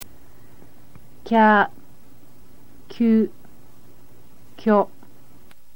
kya.mp3